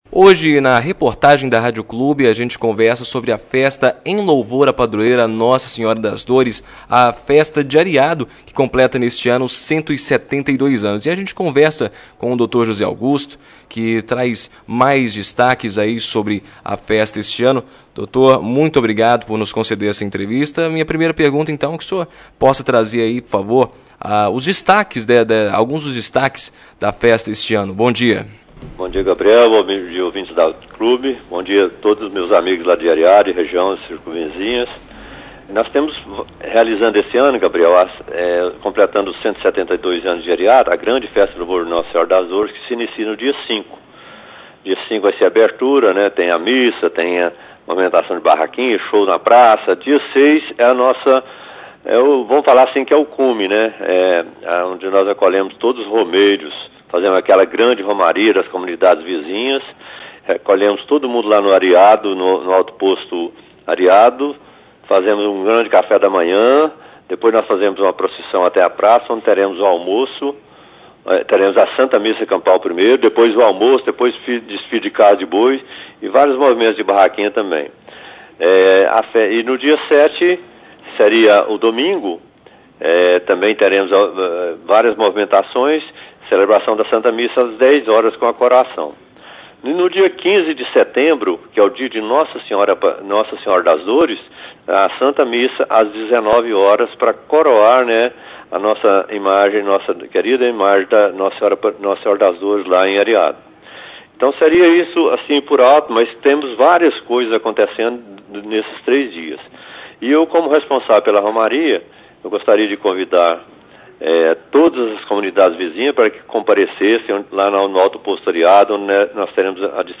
Em entrevista concedida à Rádio Clube 98